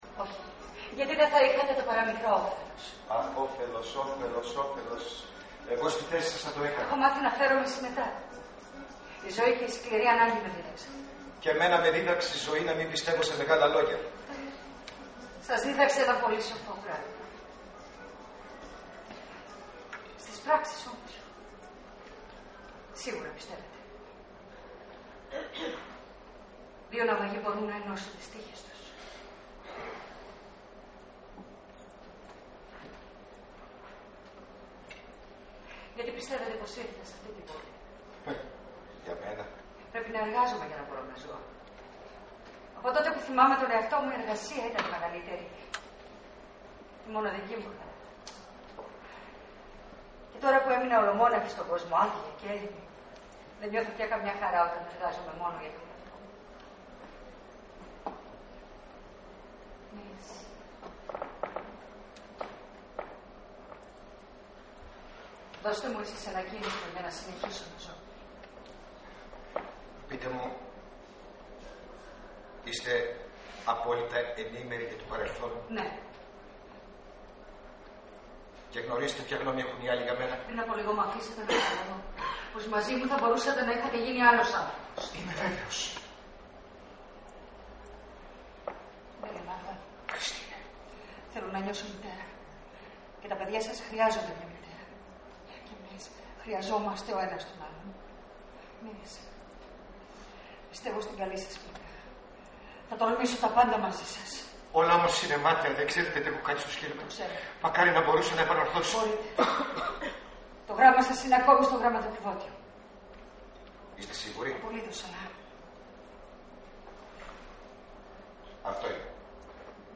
Ηχογράφηση Παράστασης